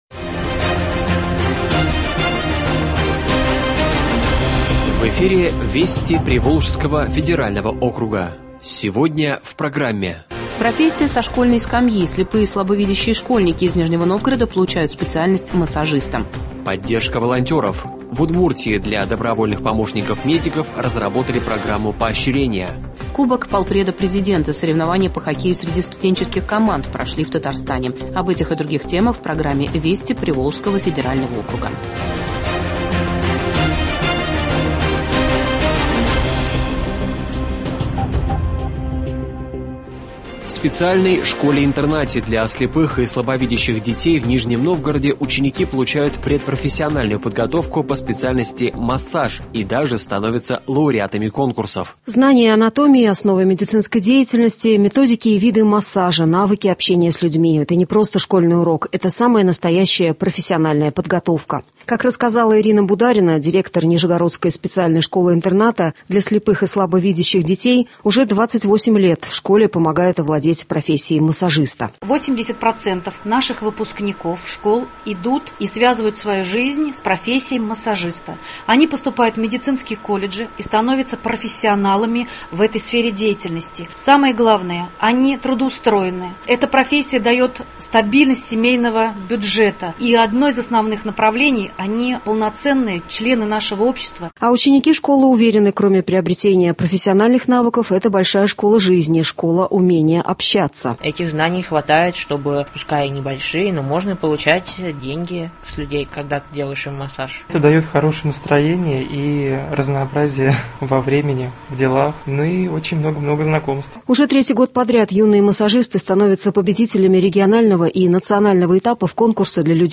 Радиообзор событий недели в регионах ПФО. Выпуск посвящен отмечаемому сегодня Международному Дню инвалидов.